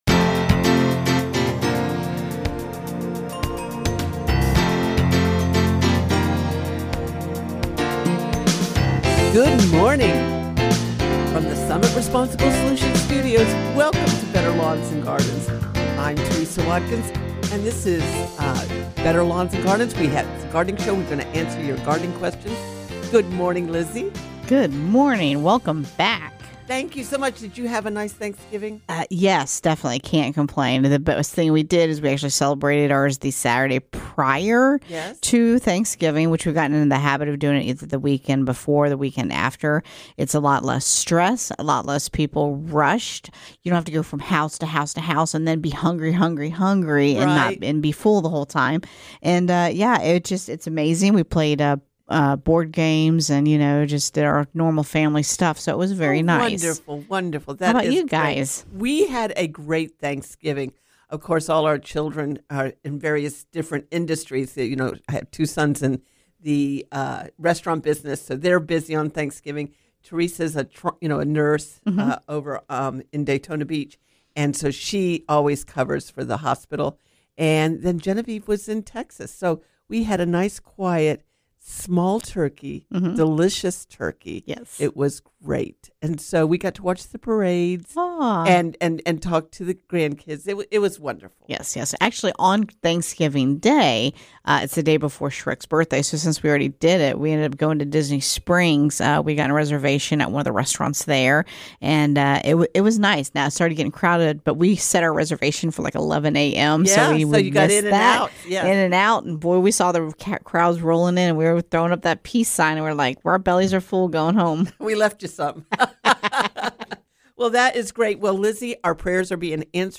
Better Lawns and Gardens Hour 1 – Broadcasting live from the Summit Responsible Solutions Studios.